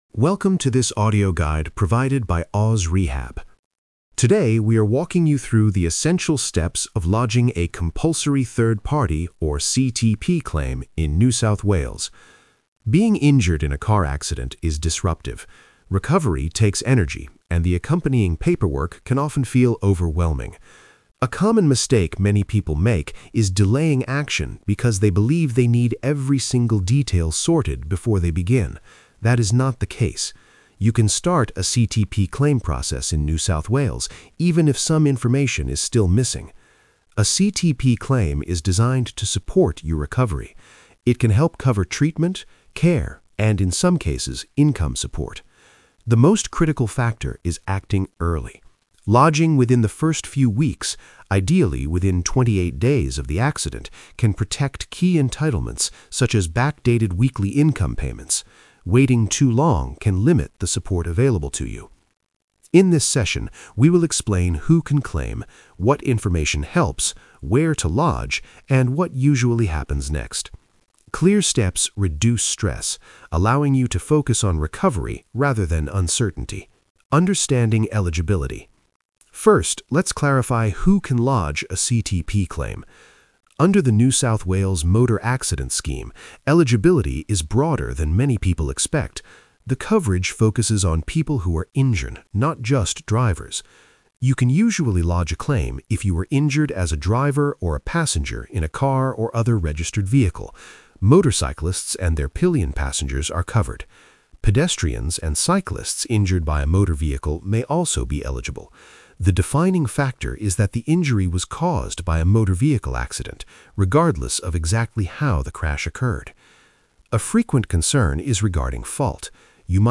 Single-host narration